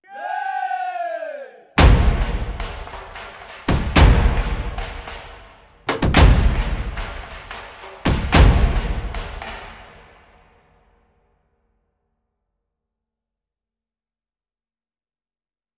Epic Tribal Drums